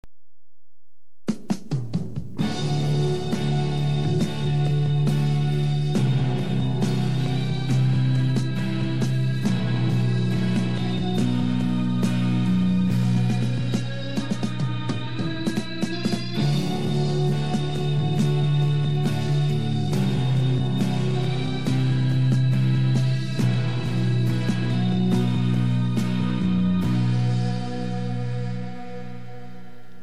変拍子のような奏法